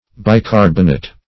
Bicarbonate \Bi*car"bon*ate\, n. [Pref. bi- + carbonate.]